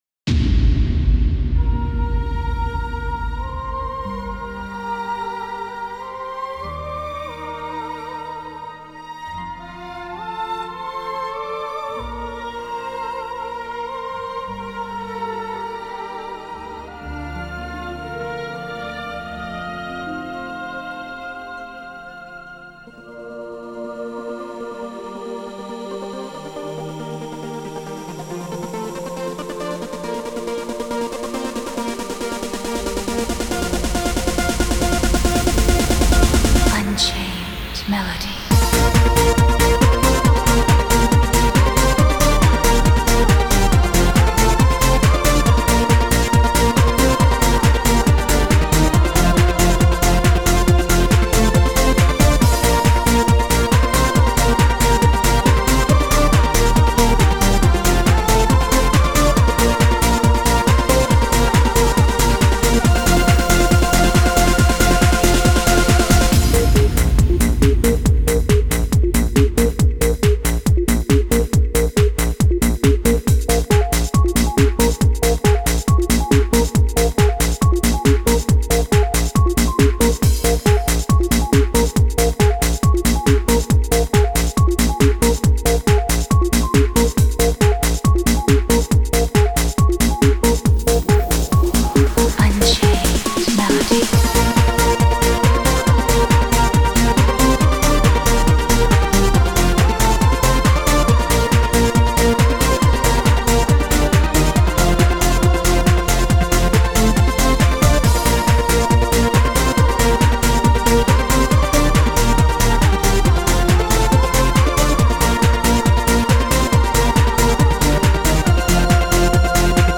Тихо,спокойно,красиво